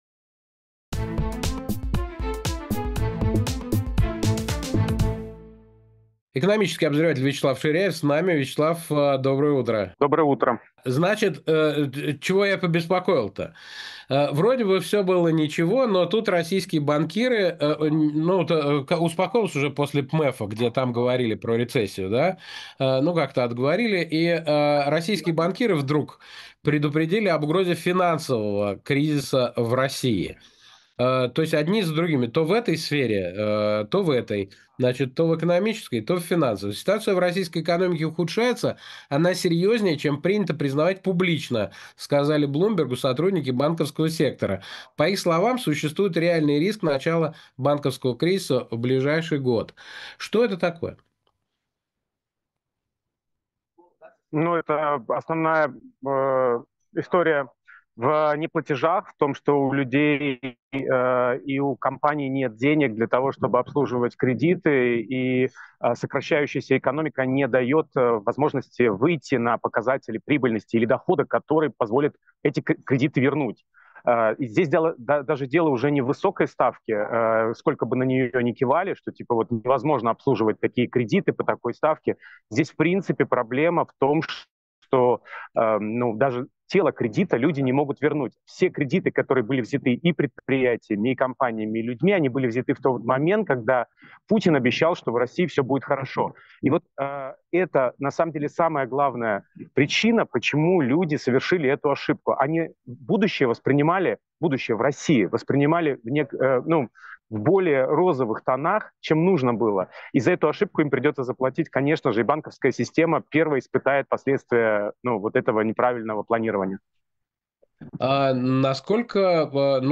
Фрагмент эфира от 27 июня.